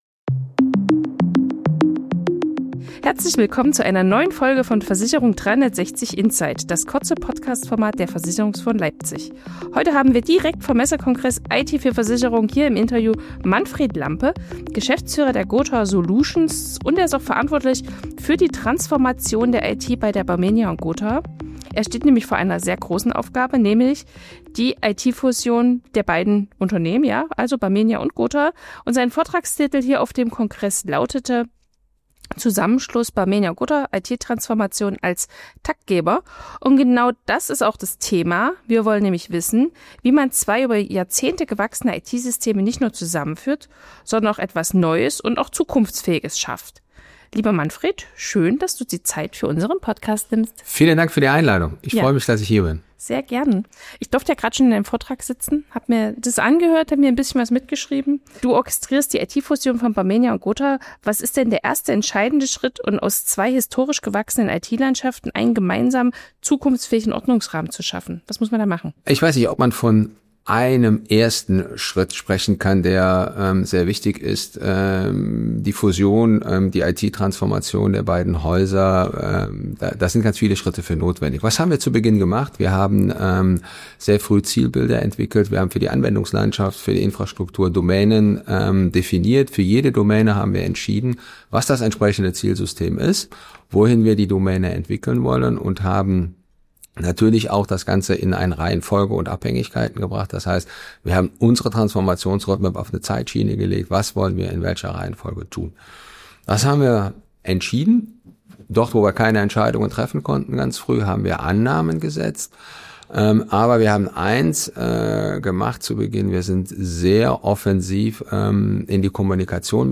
In dieser Insight-Folge vom Messekongress „IT für Versicherungen“